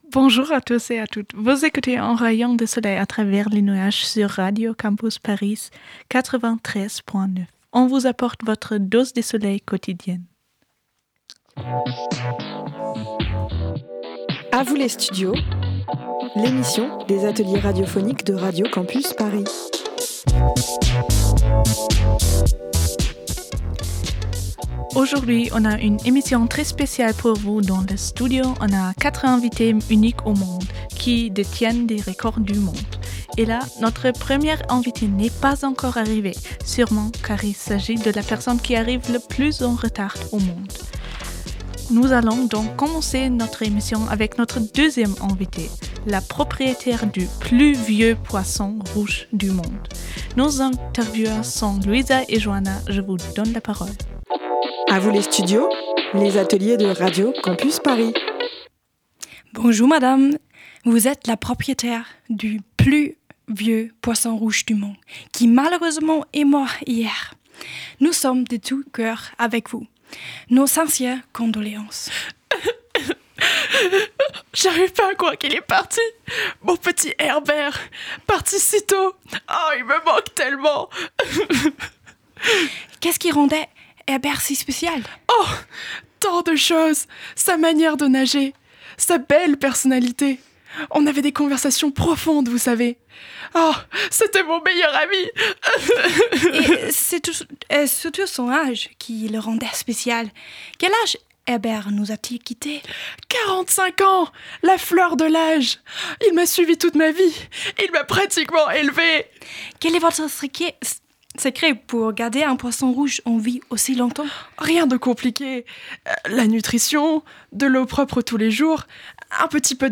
L'émission feel good des étudiant·e·s de la Sorbonne-Nouvelle et leurs correspondant·e·s de la Humboldt-Universität de Berlin où l'on interviewe quatre, enfin trois, détenteurs et détentrices de records insolites.
Musique : Queen - We Are the Champions